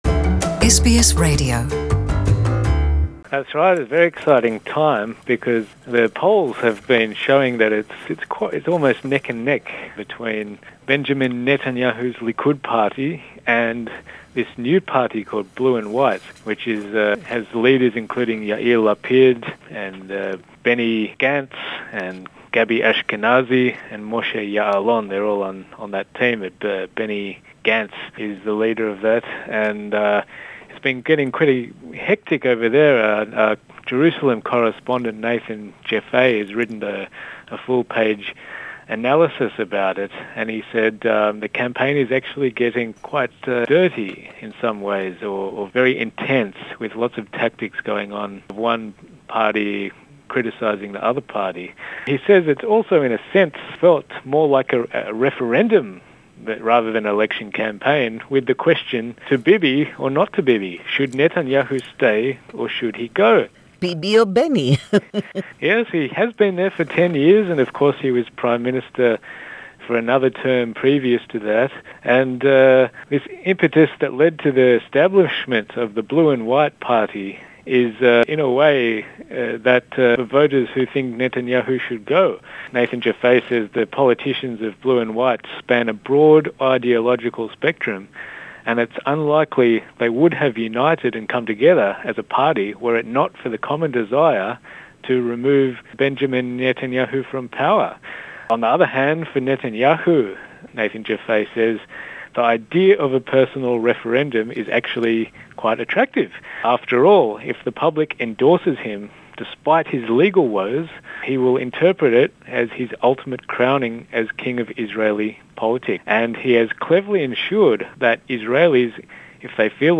I’ve opened my conversation